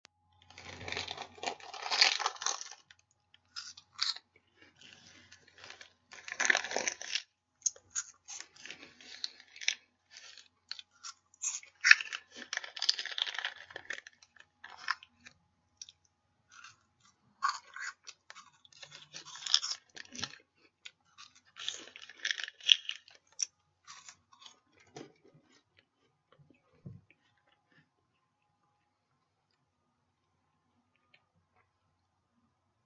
各式各样的咀嚼片
描述：在略微隔音的房间内用变焦H6以不同方式记录芯片的咀嚼。轻微的eq。
标签： 芯片 WAV 牙齿 variated 嘴里 用力咀嚼 食品 紧缩 OWI 咀嚼 咀嚼
声道立体声